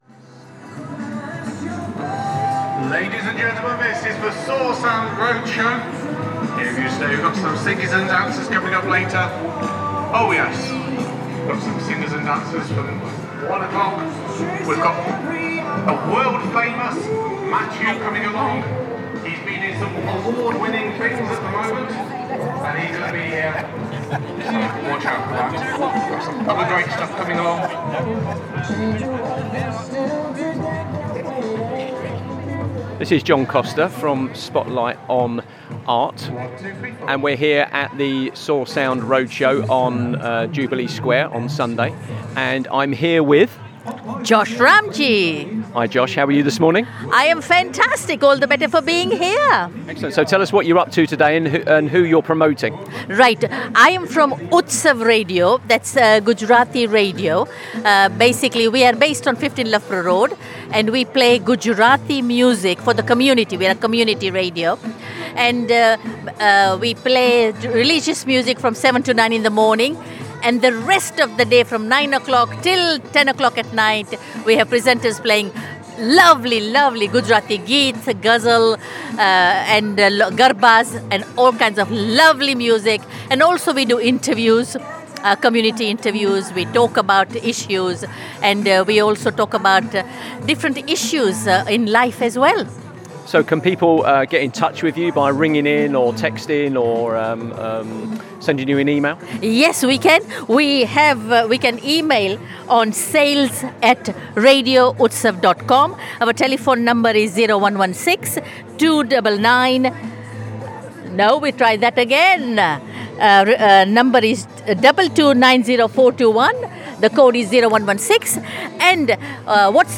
This episode of Spotlight on Arts takes us to Jubilee Square, where the Soar Sound Roadshow brought together music, dance, and an inspiring mix of community voices.